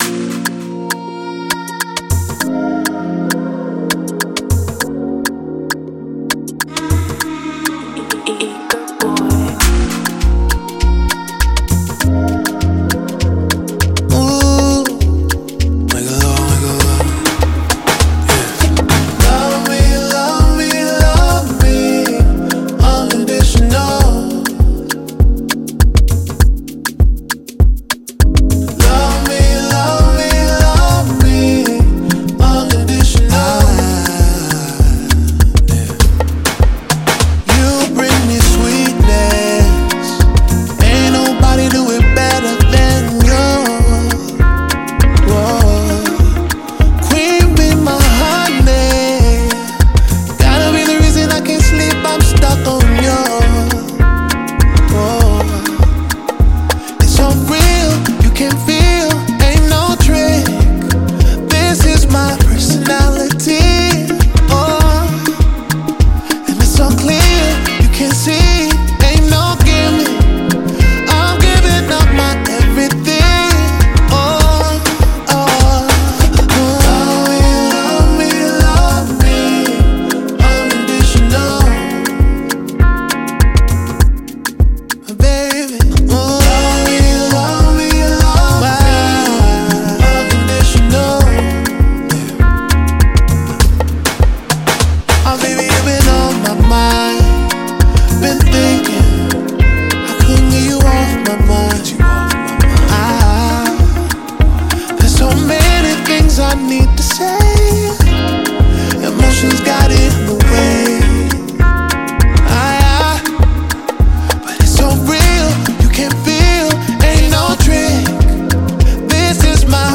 Slow Love Groove
smooth yet powerful vocal style